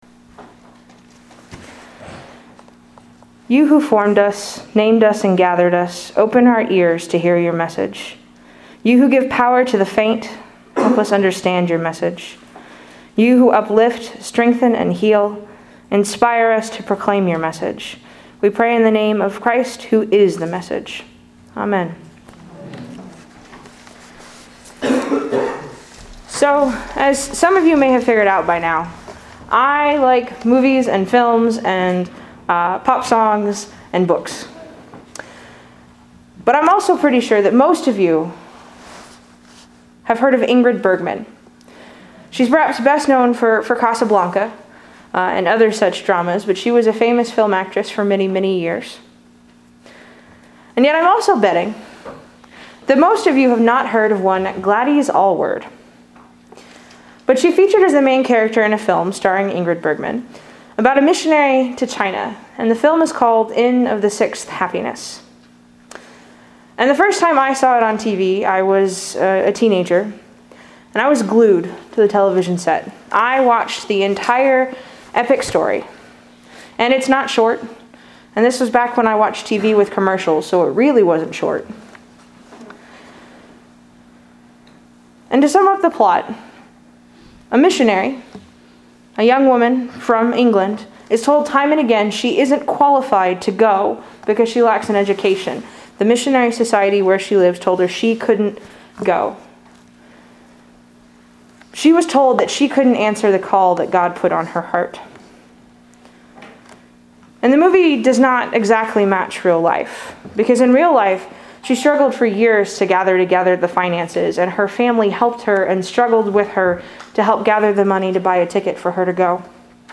Sermons 2021 | First United Methodist Church Kemp